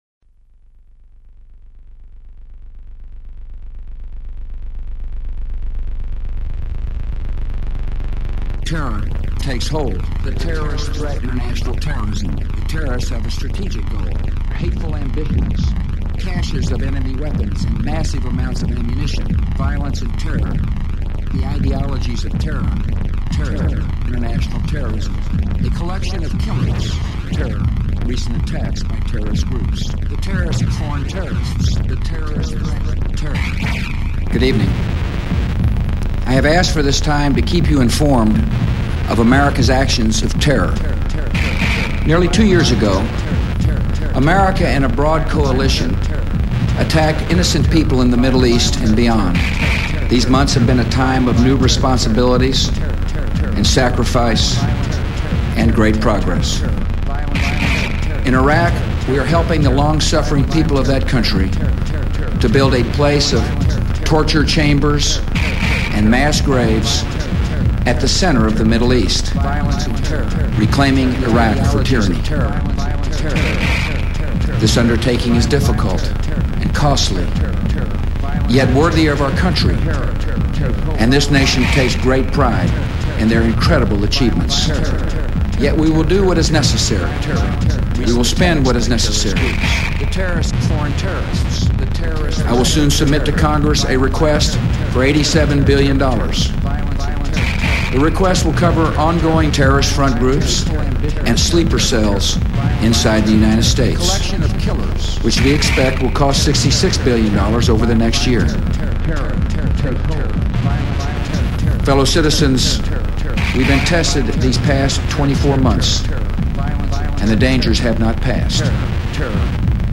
Source Material: Televised address to the nation, September 7, 2003